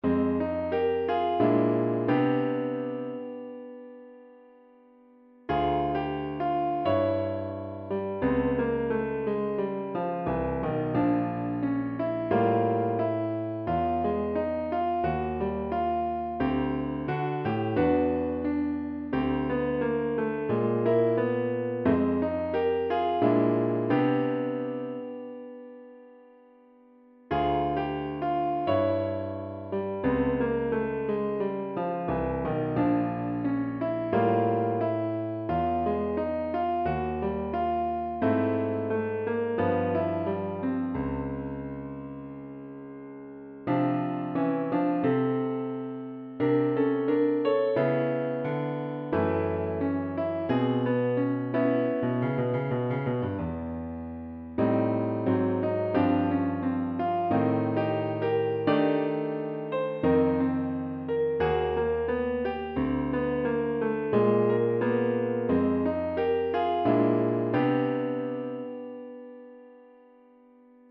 is a lovely melody with a complex harmonic structure.